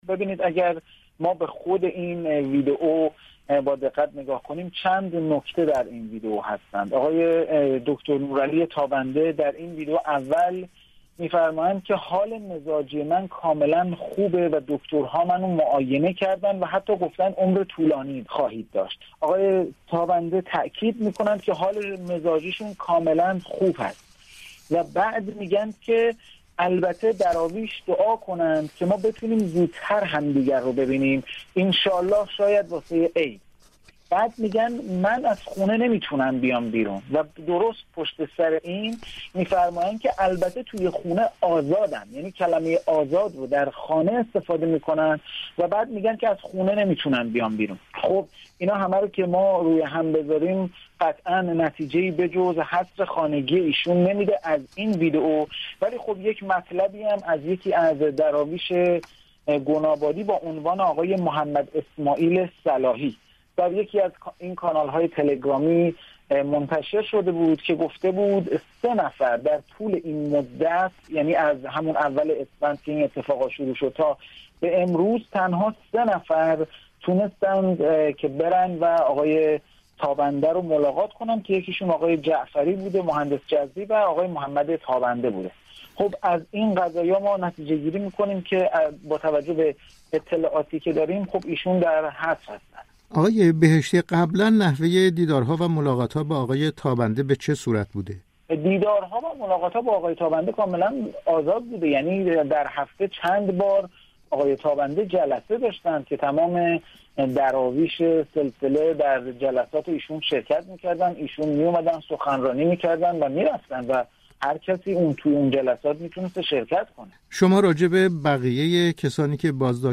گفت‌وگوی رادیو فردا